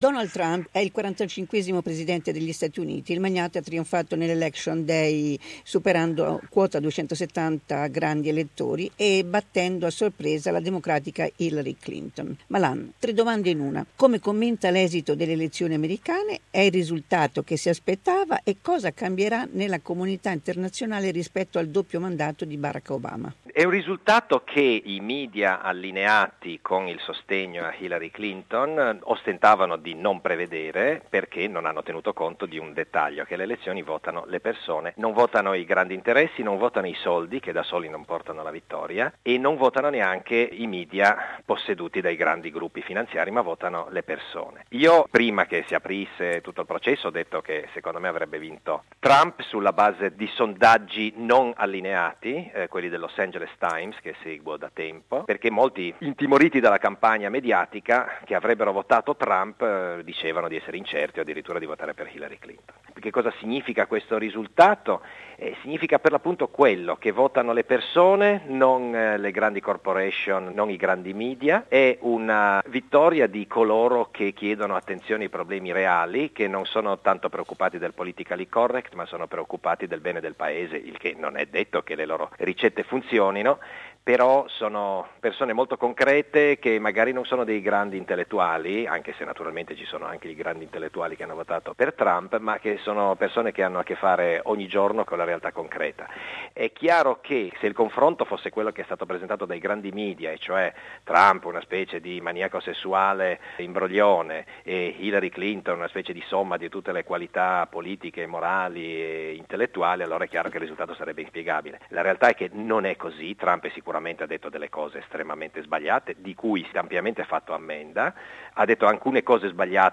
We spoke with Forza Italia Party Senator Lucio Malan about the US presidential election result and Donald Trump's victory. We also spoke about the political controversy on the 4th of December Italian constitutional referendum.